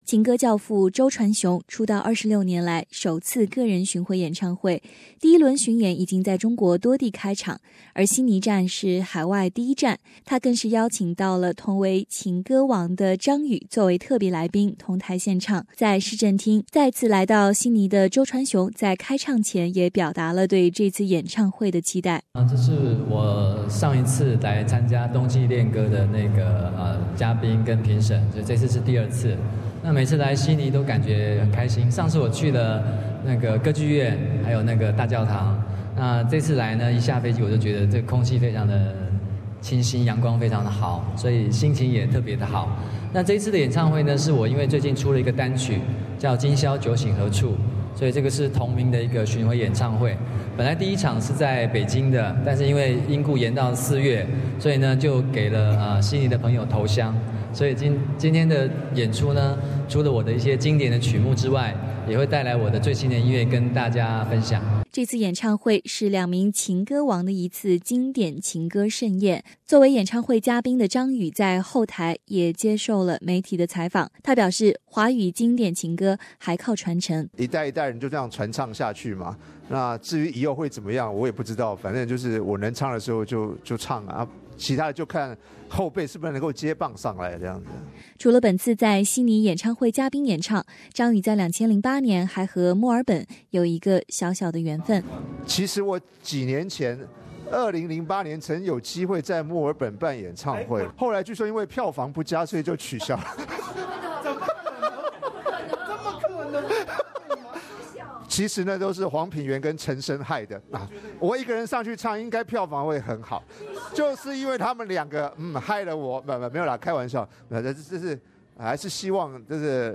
在市政厅,再次来到悉尼的周传雄在开唱前也表达了对这次演唱会的期望。 作为演唱会嘉宾的张宇在后台也接受了媒体的访问，他表示自己正在进军电影行业，完整自己在娱乐圈的最后一块拼图。